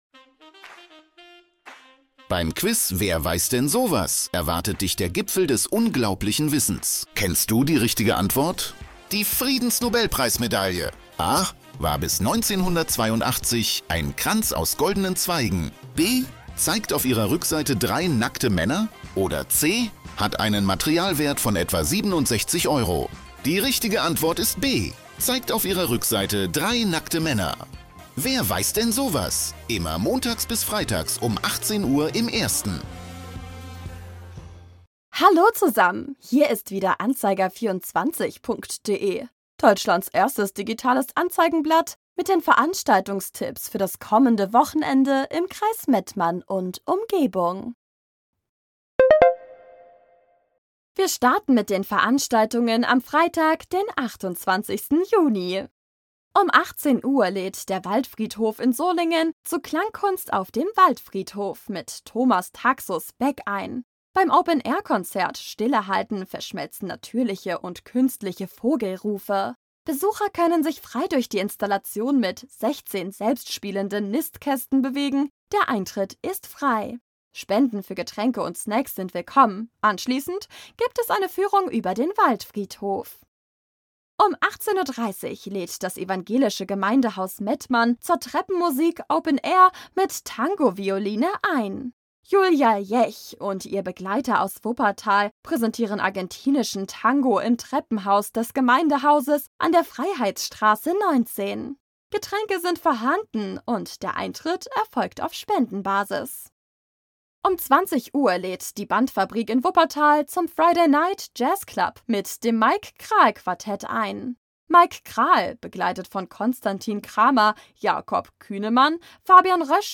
„Musik“